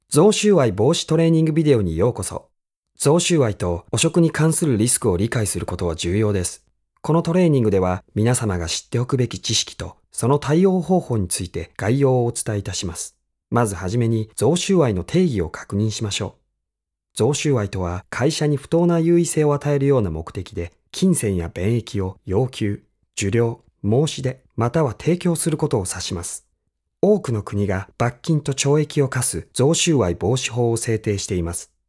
Japanese, Male, Teens-40s